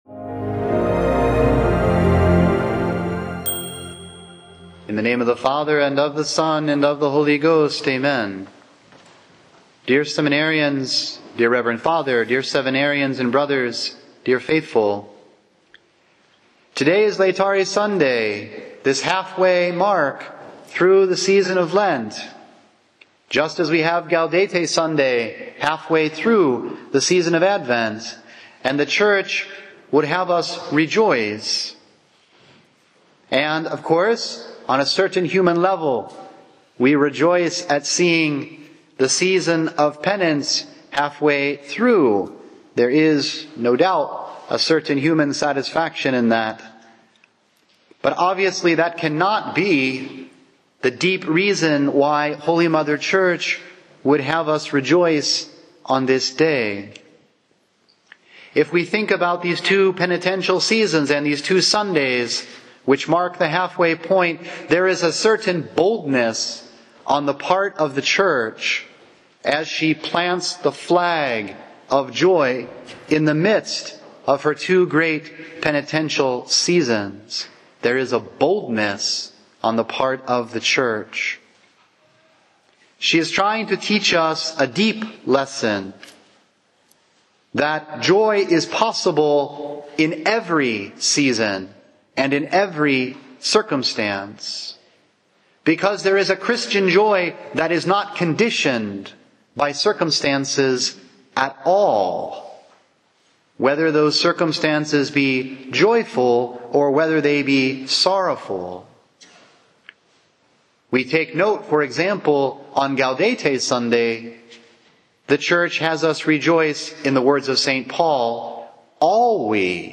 Sermon-230-Audio-converted.mp3